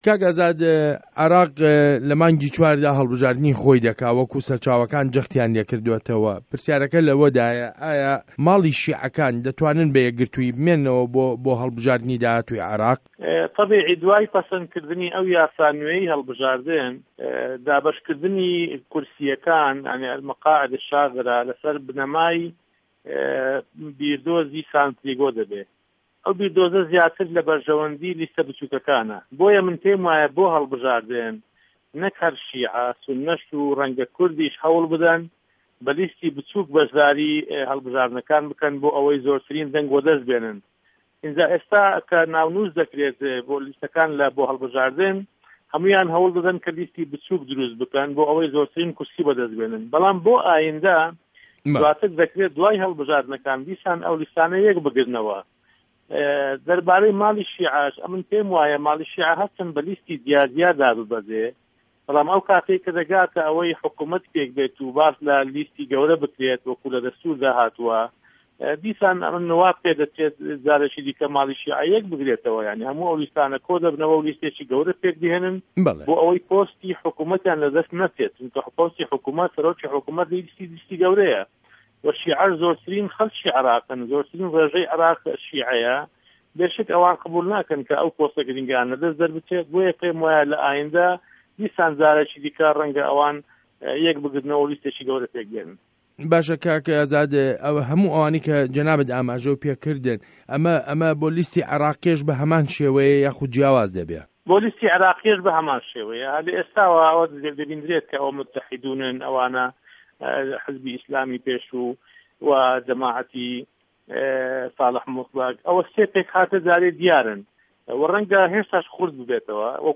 وتووێژ له‌گه‌ڵ ئازاد ئه‌بوبه‌کر